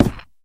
creaking_heart_place4.ogg